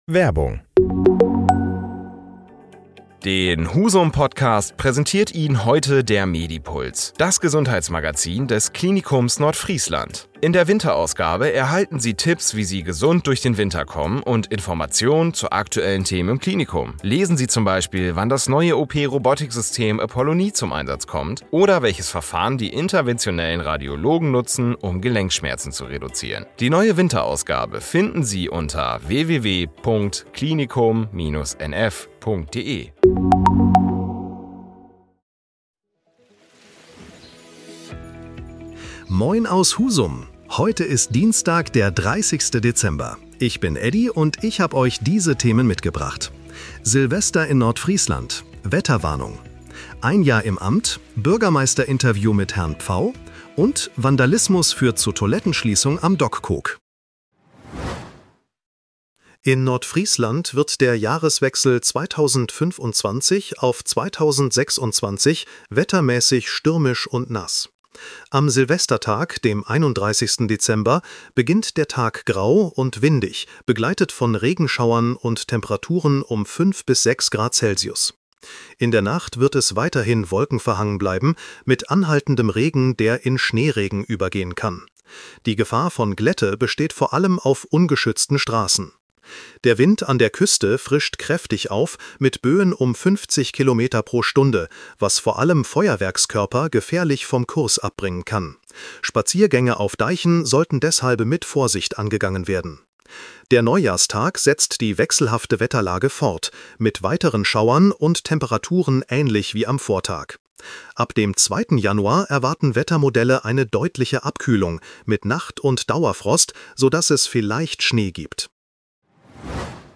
30.12. Wetterwarnung Silvester - Interview Bürgermeister Pfau - Toilettenschließung Dockkoog
Nachrichten